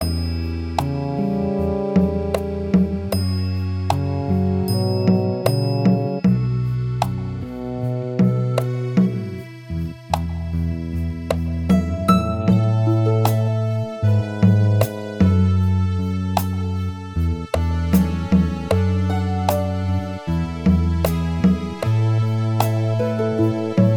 Minus Guitars Soft Rock 4:30 Buy £1.50